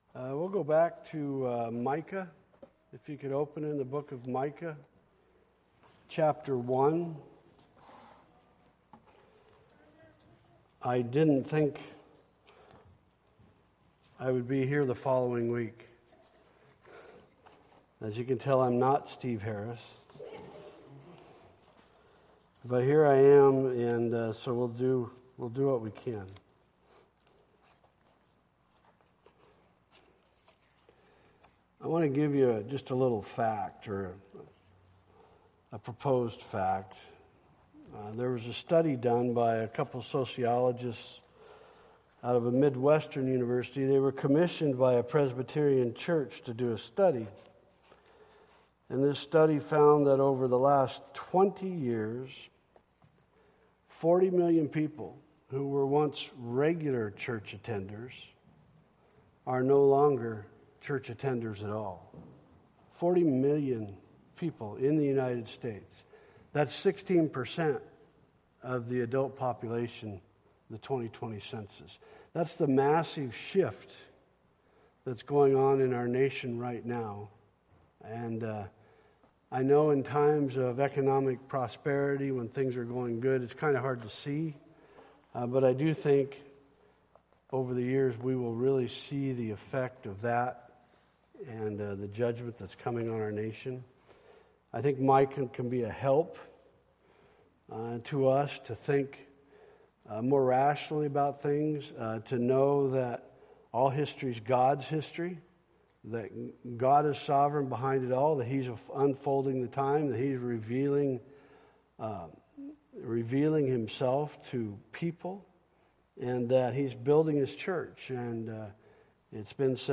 Micah 1:1-16 Service Type: Wednesday Evening Topics